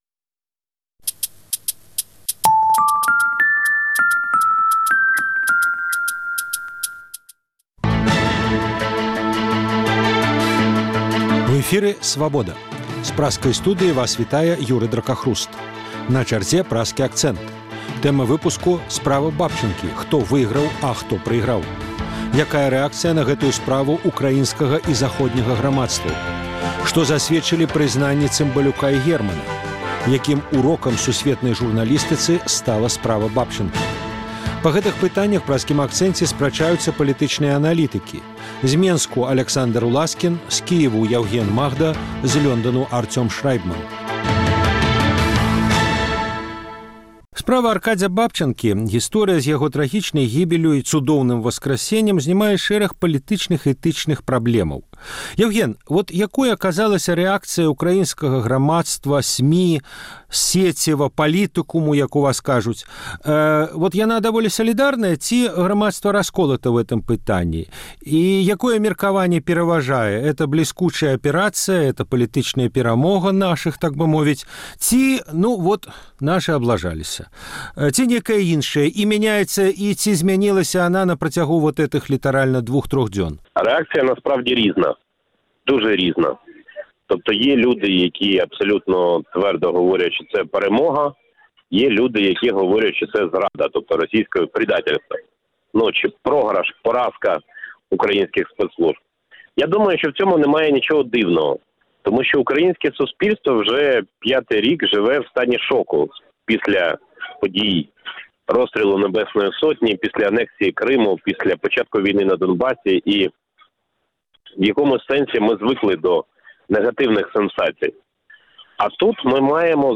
Якім урокам сусьветнай журналістыцы стала справа Бабчанкі? Па гэтых пытаньнях ў Праскім акцэнце спрачаюцца палітычныя аналітыкі